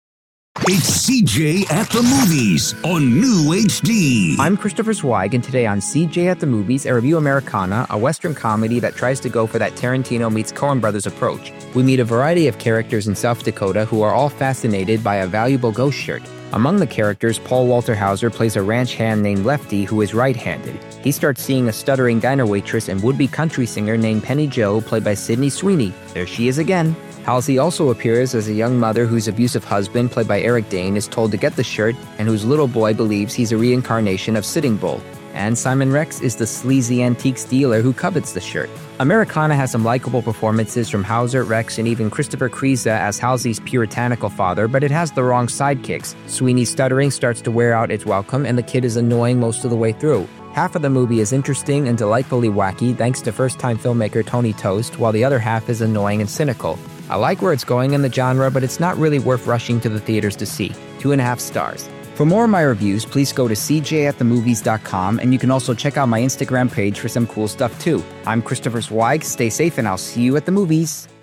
reviews five dangerous movies on the air.